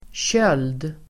Uttal: [tjöl:d]